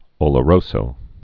(ōlə-rōsō, -zō)